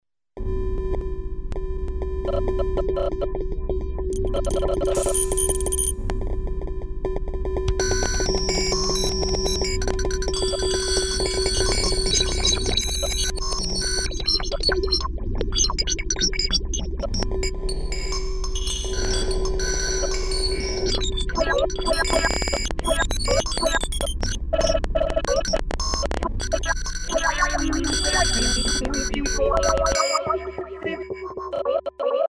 ■エレクトロニカっぽいやつを作ってみた
そんなわけで、無料シーケンサーソフトをダウンロードしていじっていたら自然に曲っぽくなってたのでアップしときます。
こういうのをエレクトロニカって云うんですよね？